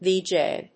ジェーブイ